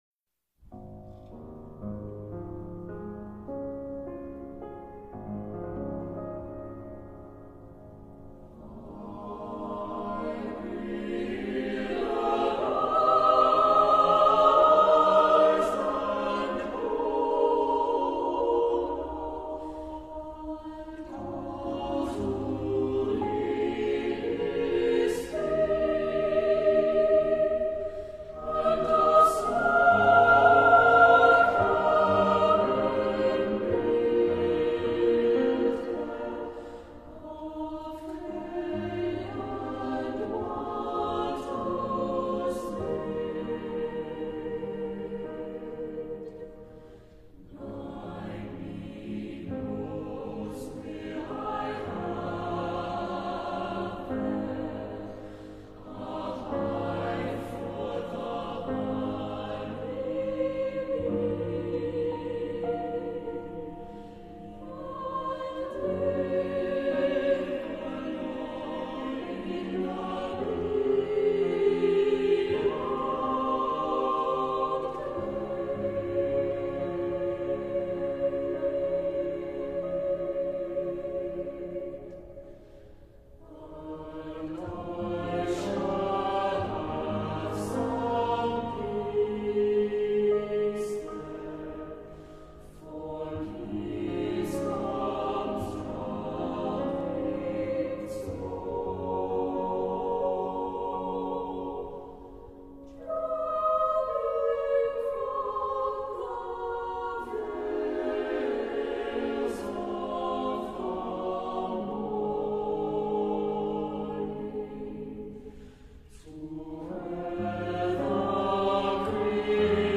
Accompaniment:      Piano
Music Category:      Choral